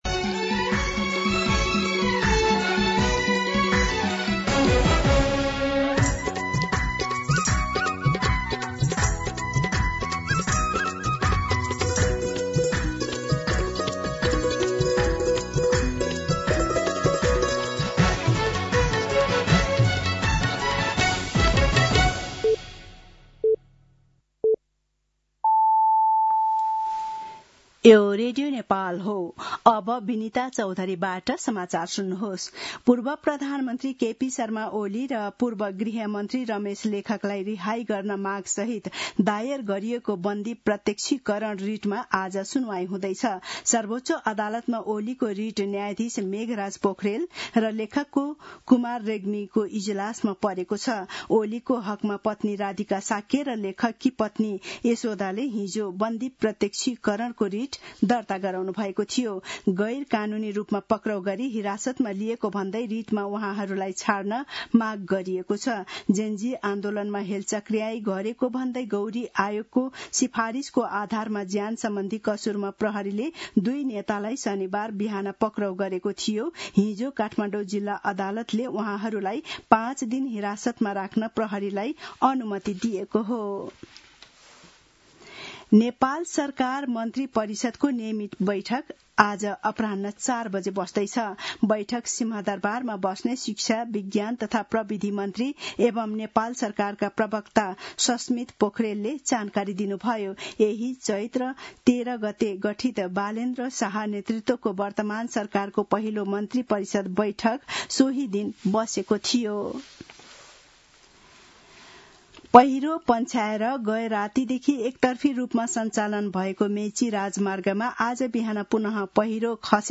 दिउँसो १ बजेको नेपाली समाचार : १६ चैत , २०८२